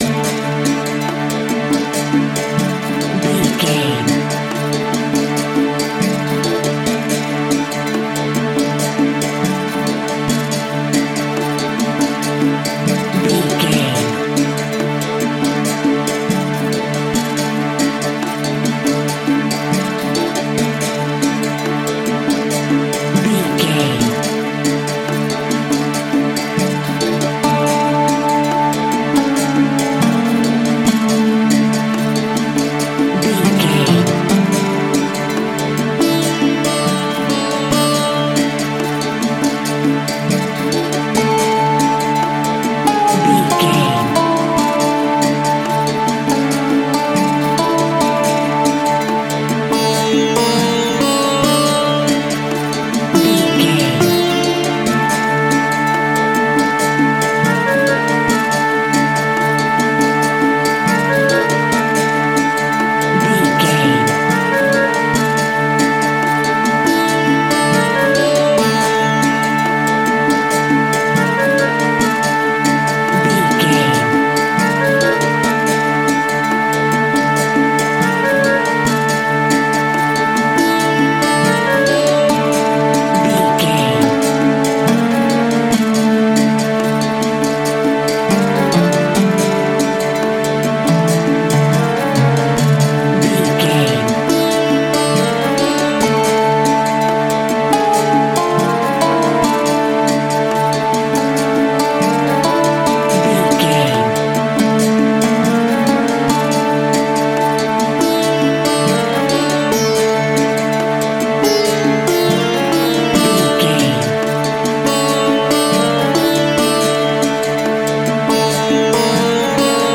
Aeolian/Minor
Slow
sitar
bongos
sarod
tambura